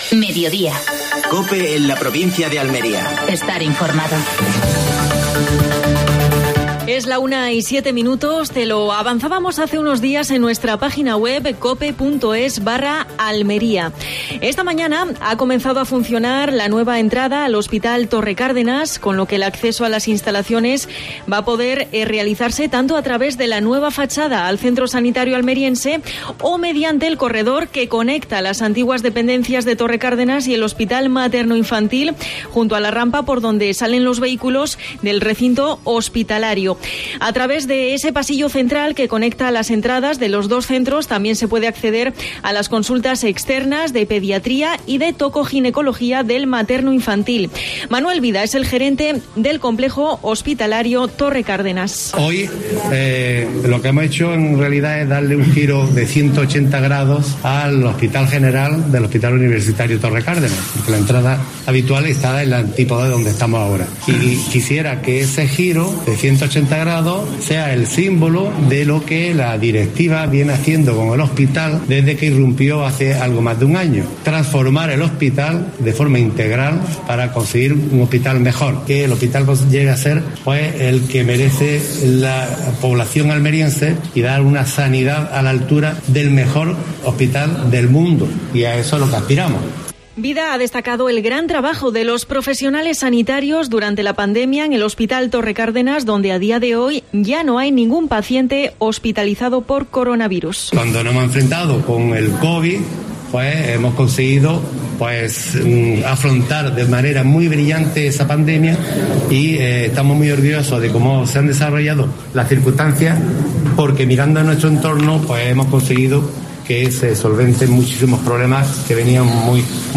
AUDIO: Actualidad en Almería. Entrevista a Óscar Liria (diputado de Fomento).